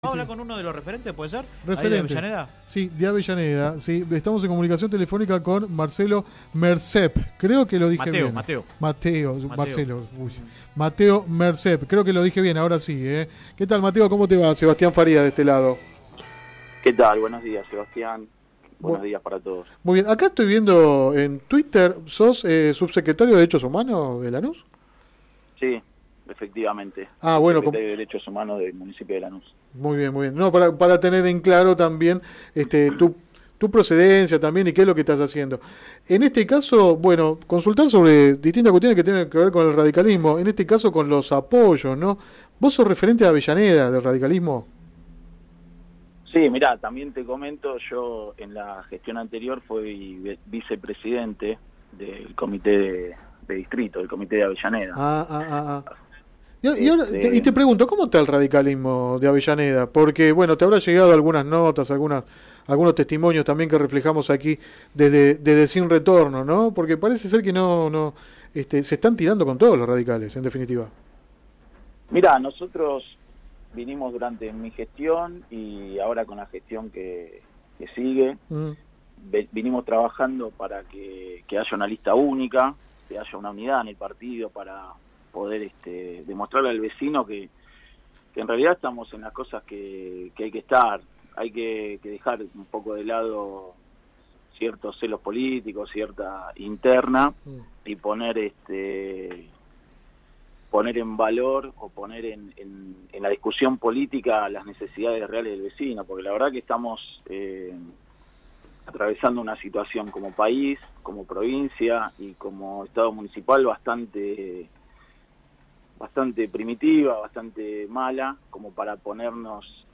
Mateo Mercep, subsecretario de Derechos Humanos de Lanús y dirigente de la UCR de Avellaneda, habló en el programa radial Sin Retorno (lunes a viernes de 10 a 13 por GPS El Camino FM 90 .7 y AM 1260).
entrevista radial